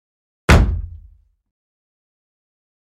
Звуки удара по столу
Сильно